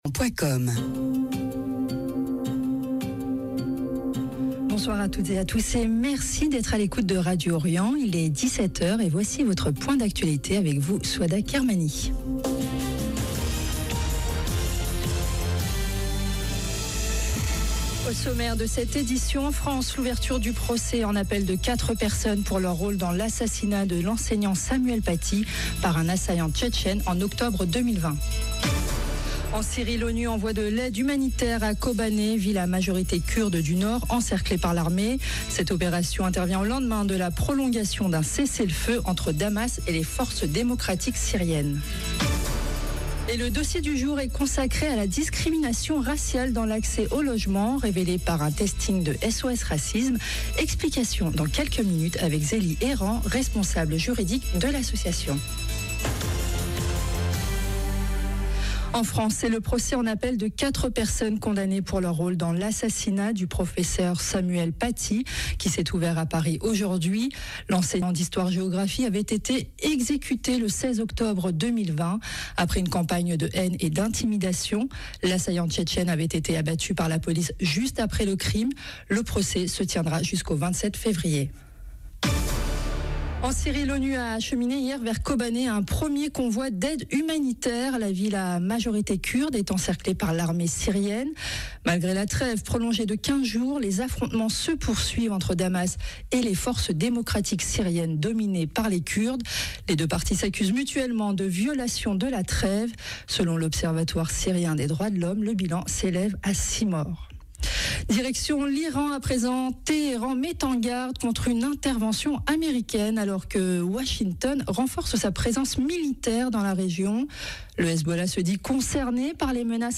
France Syrie Journal 26 janvier 2026 - 8 min 52 sec France : procès en appel dans l’affaire Samuel Paty, Syrie : aide humanitaire acheminée à Kobané Radio Orient Journal de 17H Au sommaire: En France l’ouverture du procès en appel de quatre personnes pour leur rôle dans l’assassinat de l’enseignant Samuel Paty, par un assaillant tchétchène en octobre 2020. En Syrie, l’ONU envoie de l’aide humanitaire à Kobané, ville à majorité kurde du nord encerclée par l’armée.